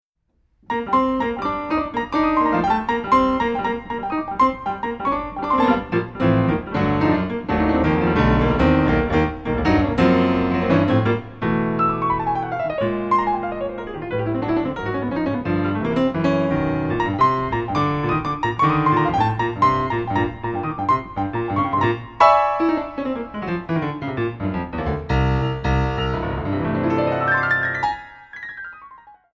Solo Piano Concert
Recording: Ralston Hall, Santa Barbara, CA, January, 2008
Piano
a fast tempo, high energy treatment